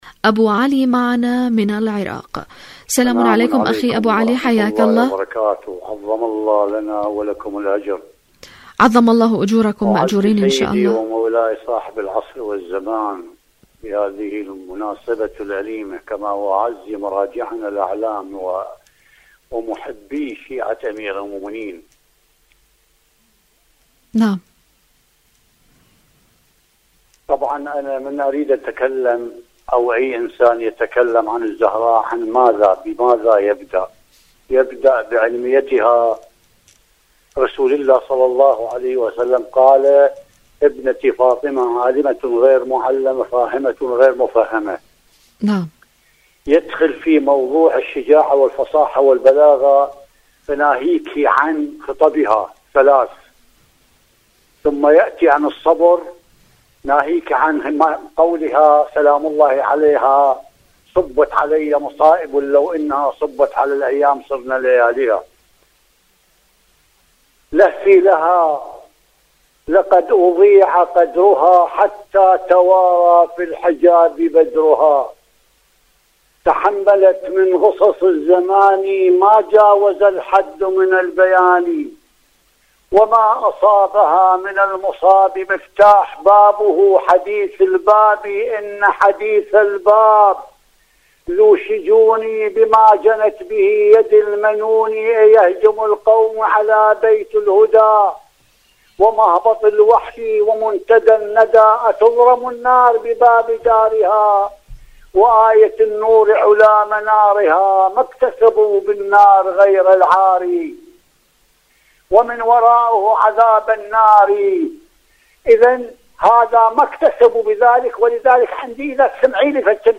مشاركه هاتفية